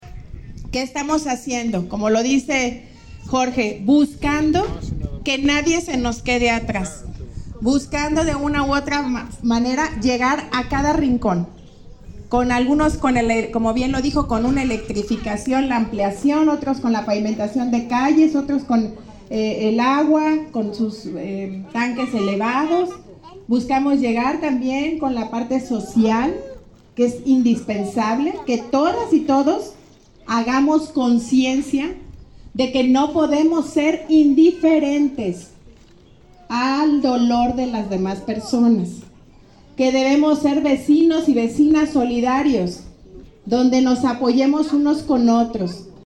Lorena Alfaro, Gracía, presidenta municipal